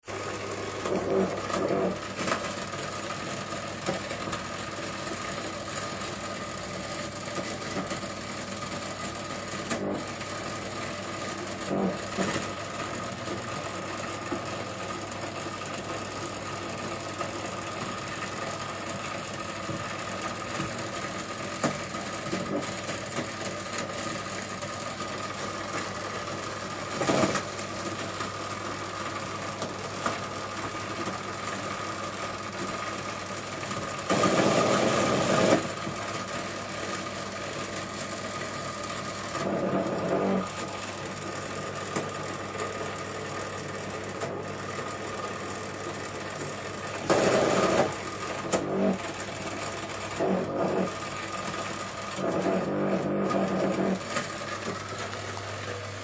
描述：我做了机器人演讲......
标签： 讲话 星系 电子人 数据 语音 机器人 机器人 仿生 谈话 语音 计算机 外星人 机器人 机械的 小工具 空间 机器人 机器
声道立体声